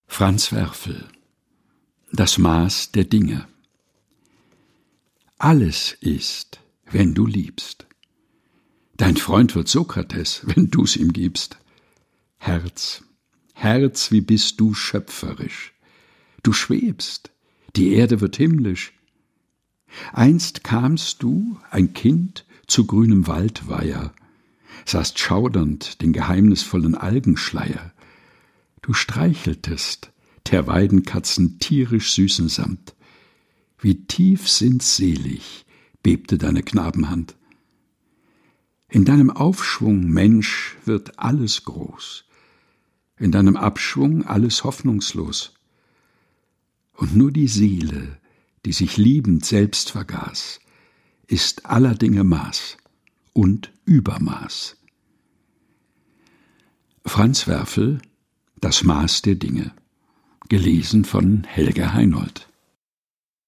liest sie in seinem eigens zwischen Bücherregalen eingerichteten, improvisierten Studio ein.